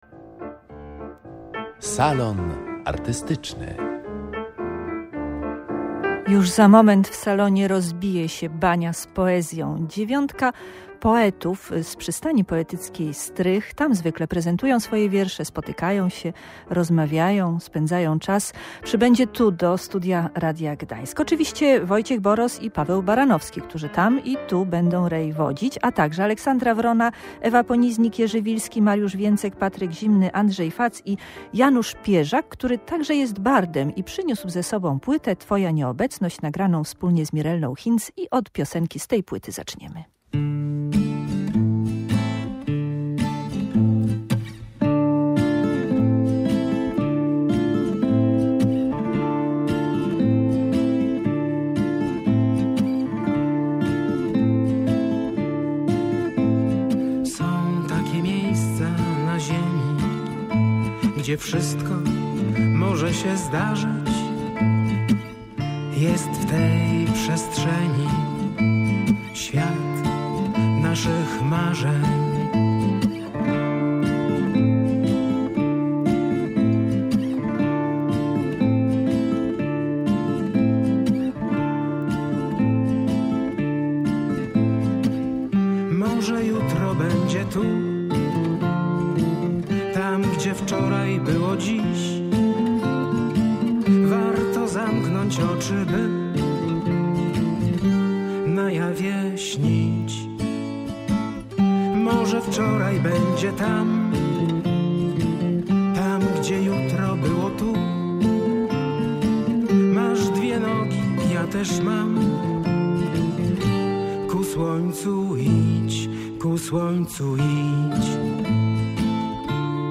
Przynieśli swoje wiersze, piosenki i opowieści.